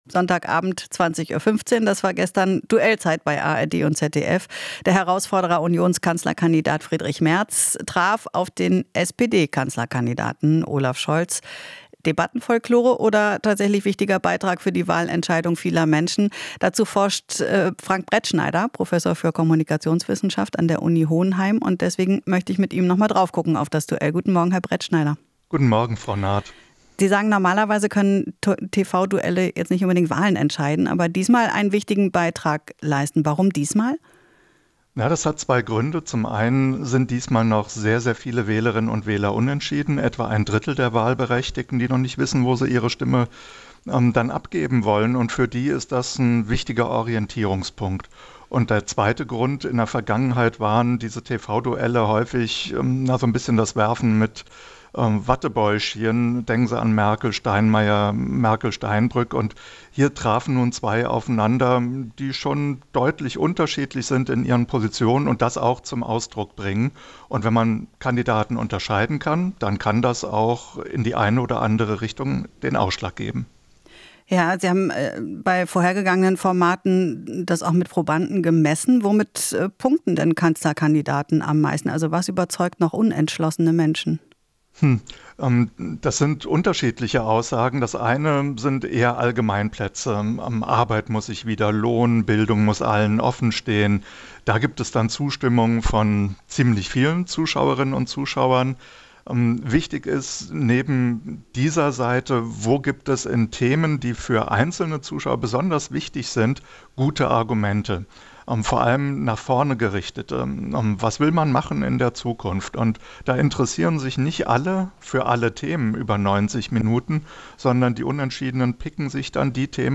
Interview - Kommunikationswissenschaftler: Kein Sieger im TV-Duell